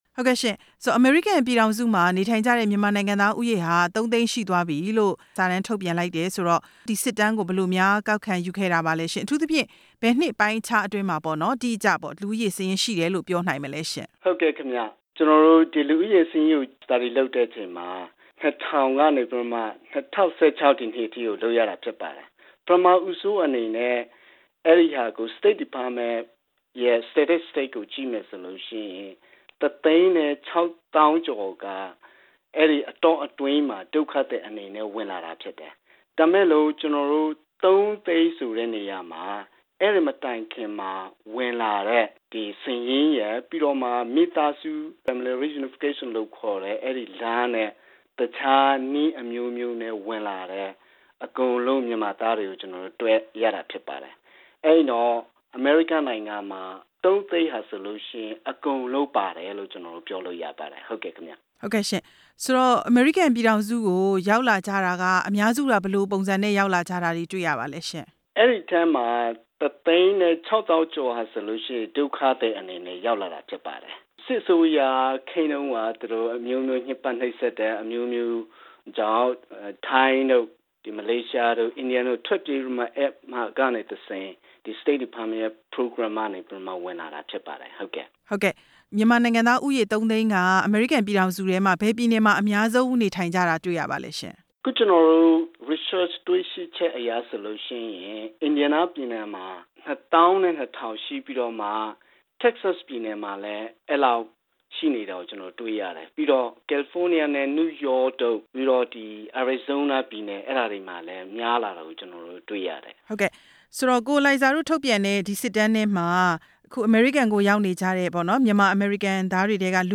အမေရိကန်မှာ မြန်မာလူဦးရေ ၃ သိန်းကျော်အထိ မြင့်တက်လာမှု မေးမြန်းချက်